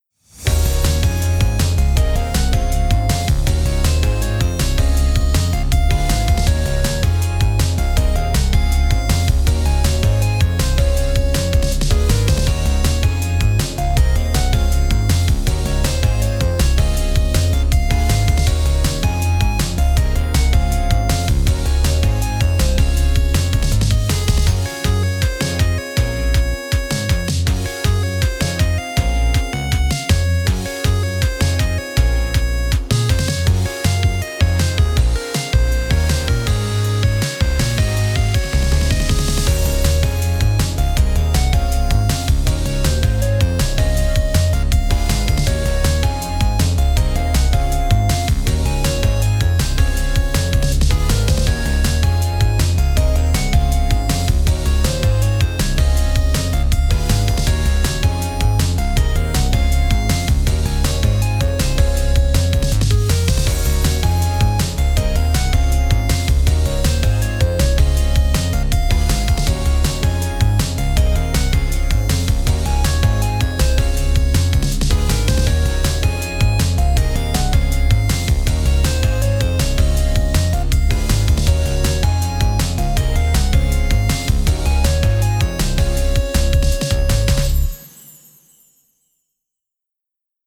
かわいい, のんびり, ほのぼの, ゆったり, コミカル, ループ対応, 日常, 明るい